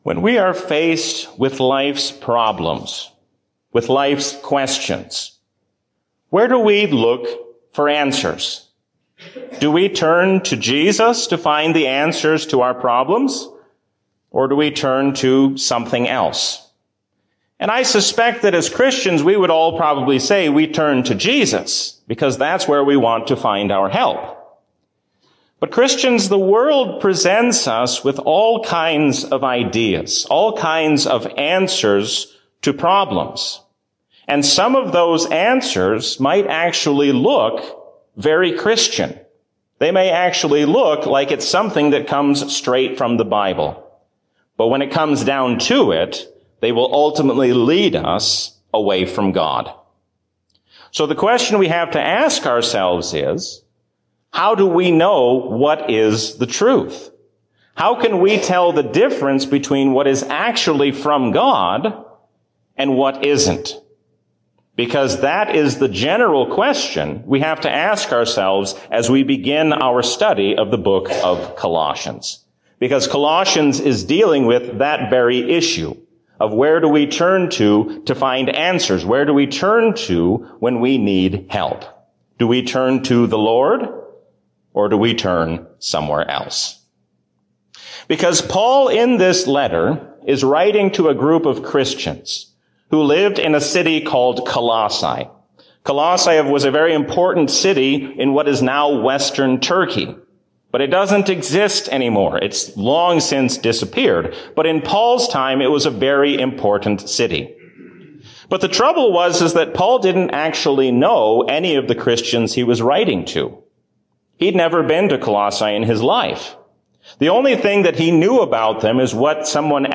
A sermon from the season "Trinity 2024." Let us not be double minded, but single minded in our devotion toward God.